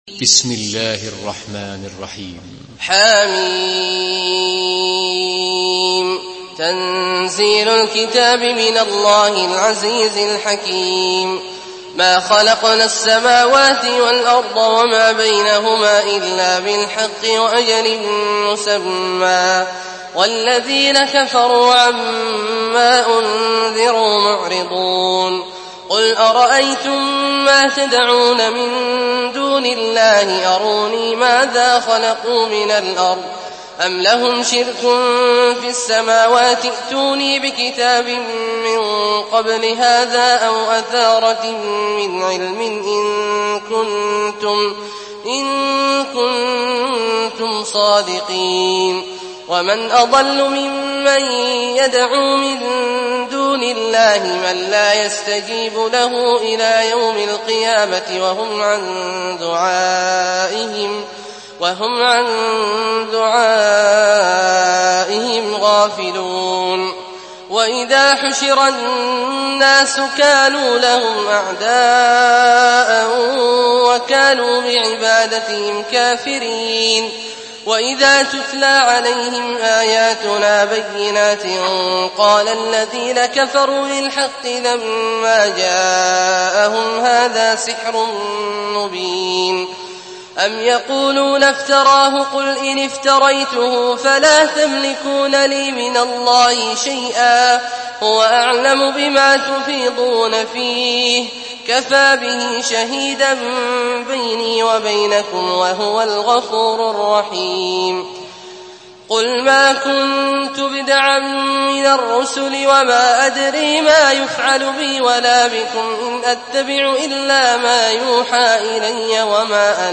Surah Ahkaf MP3 by Abdullah Al-Juhani in Hafs An Asim narration.
Murattal Hafs An Asim